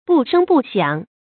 不聲不響 注音： ㄅㄨˋ ㄕㄥ ㄅㄨˋ ㄒㄧㄤˇ 讀音讀法： 意思解釋： 不說話，不出聲 出處典故： 茅盾《子夜》十一：「偶或有獨自低著頭 不聲不響 的，那一定是失敗者。」